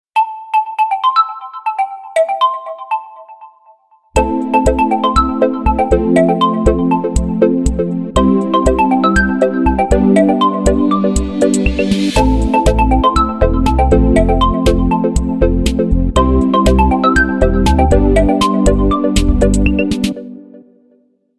• Качество: 320, Stereo
мелодичные
без слов
Electronica
звонкие